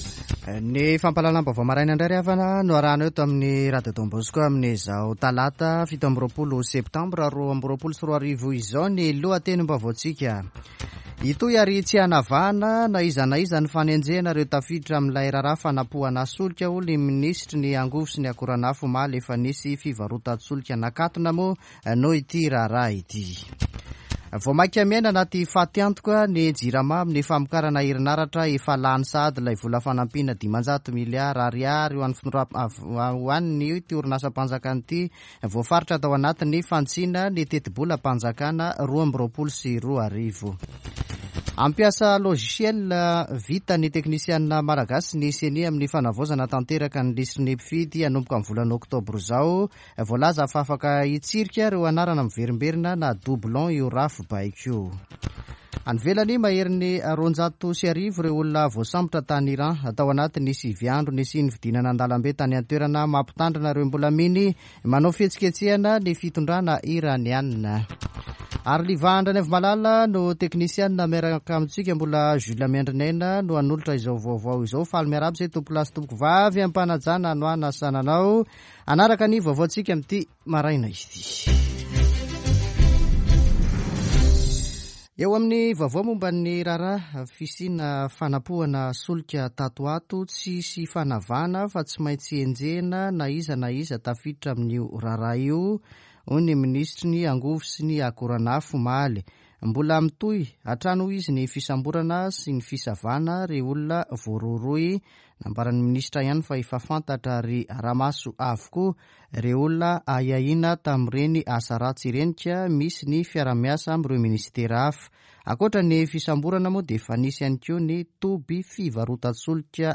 [Vaovao maraina] Talata 27 septambra 2022